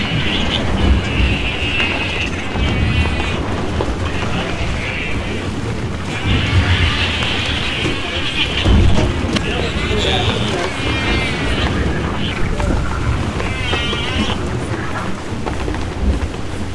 Albatros frente blanca.wav